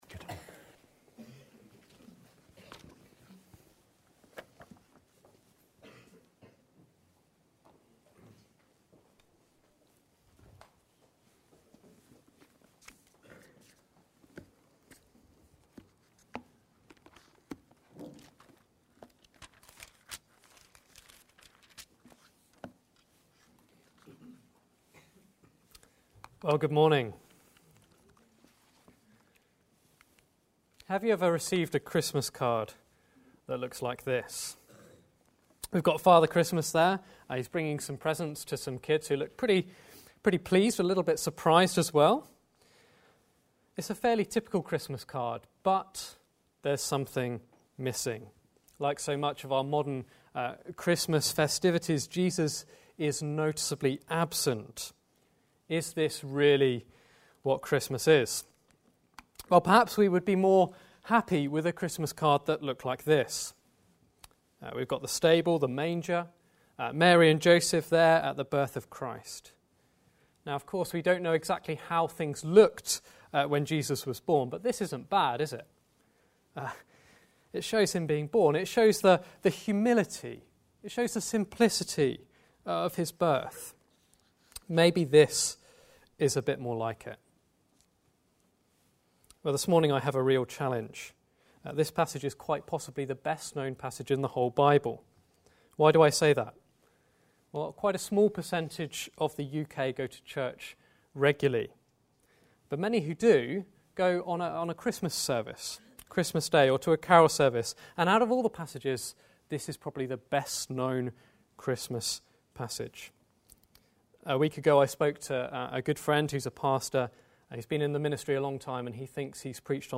Single Sermon | Hope Church Goldington